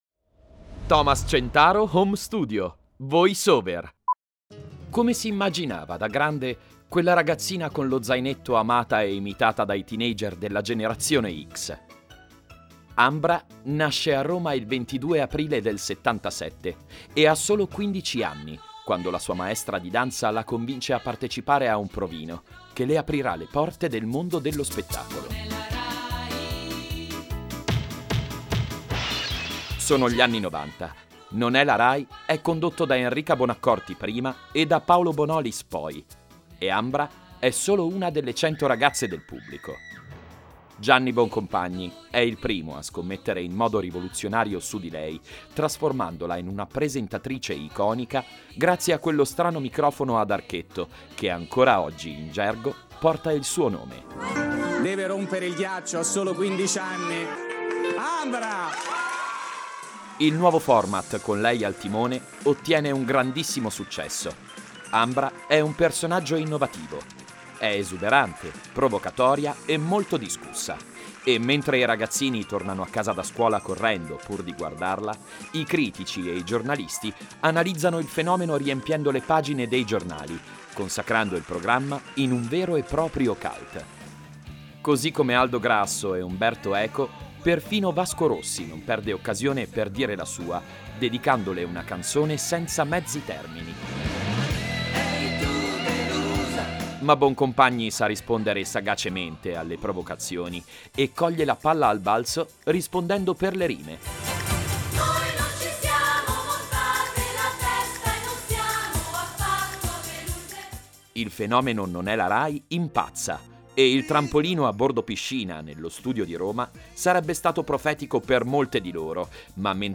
ITALIAN MALE VOICE OVER ARTIST
I own the latest technology equipment wrapped in a soundproof environment for high quality professional recordings that guarantee a result in line with the prestige of the brand to be promoted.
VOICE OVER